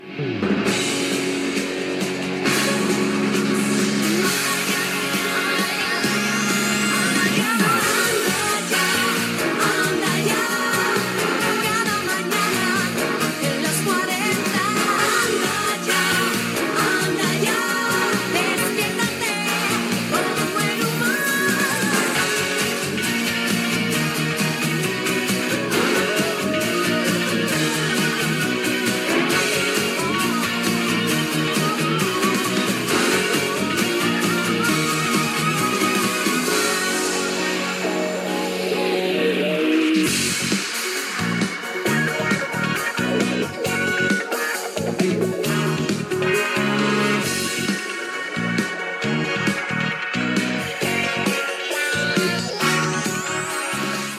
Sintonia del programa